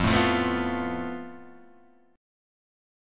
FMb5 chord